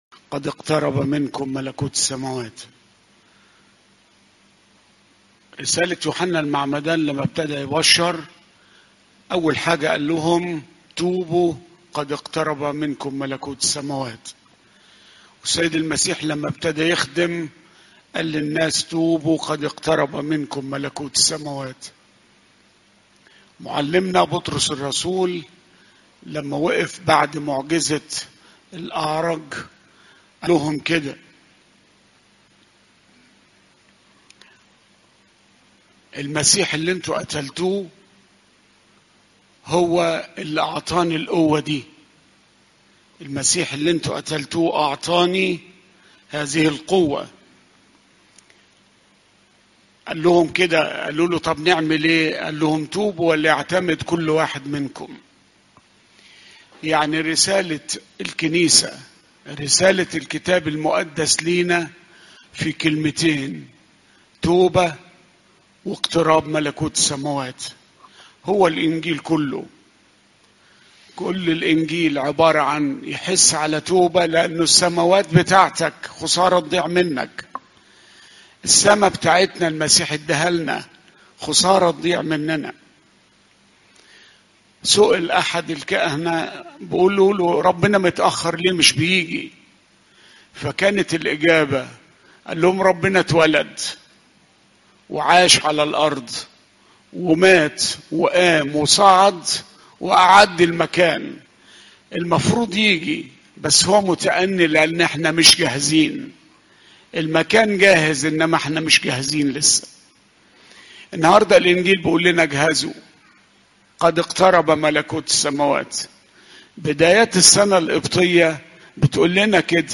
عظات قداسات الكنيسة